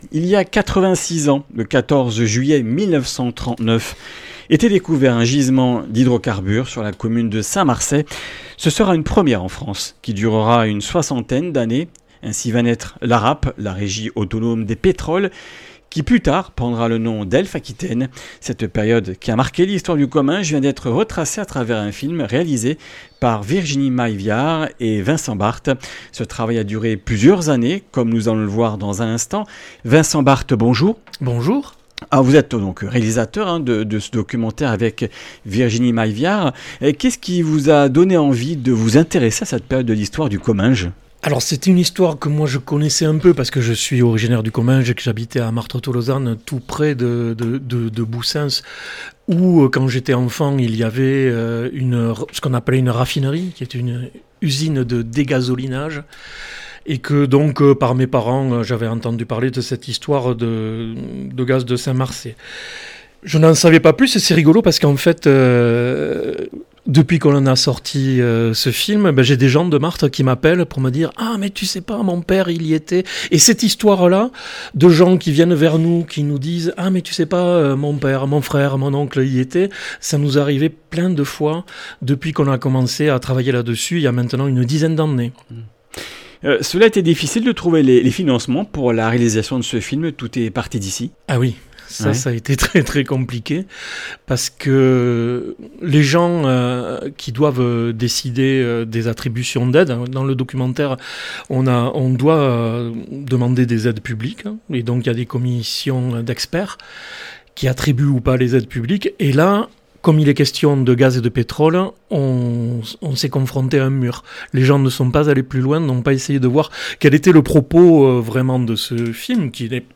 Comminges Interviews du 08 juil.